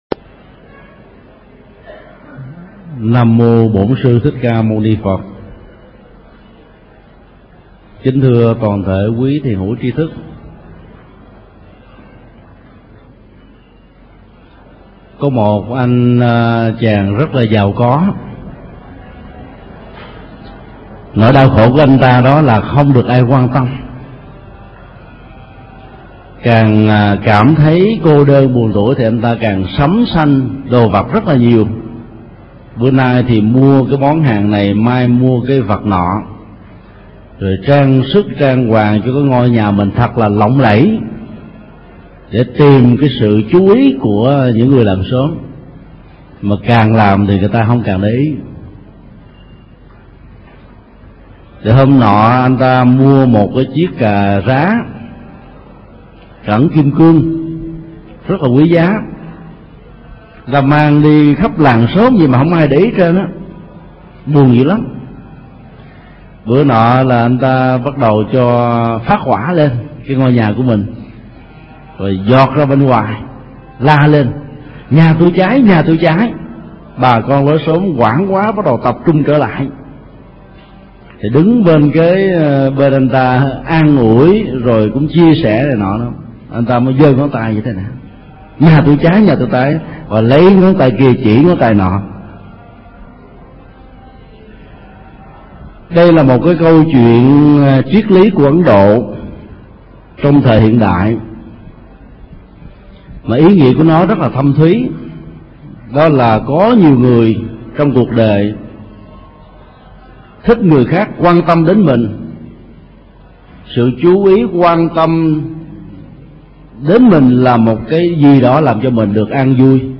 Mp3 Thuyết Pháp Kinh Trung Bộ 5 (Kinh Không Uế Nhiễm) – Tâm lý vị ngã trung tâm